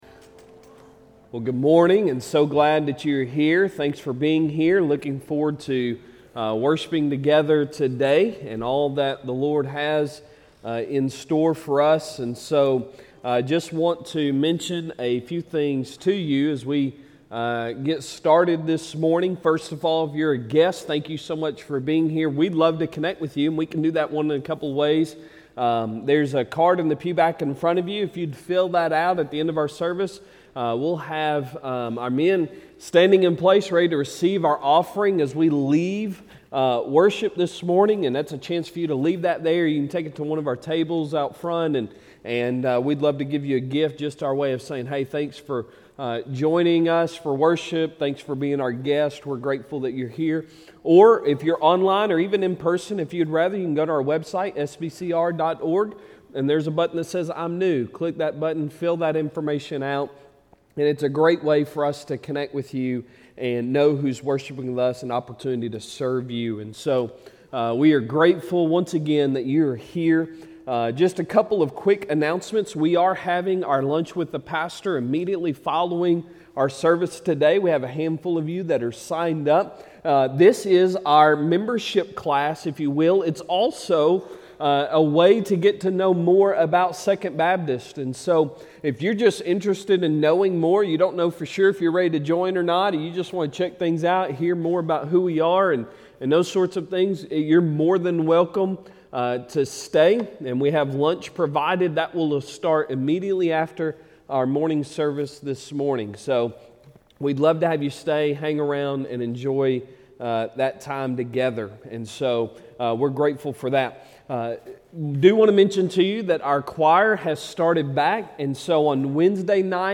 Sunday Sermon August 28, 2022